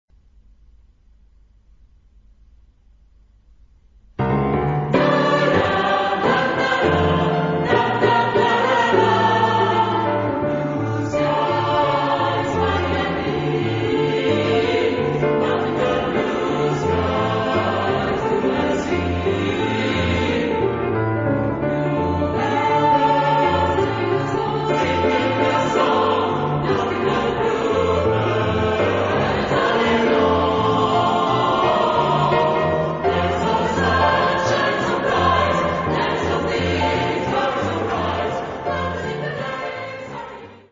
Genre-Style-Form: Secular ; Choral jazz ; Jazz standards
Mood of the piece: swing
Type of Choir: SATB  (4 mixed voices )
Instruments: Piano (1)
Tonality: G minor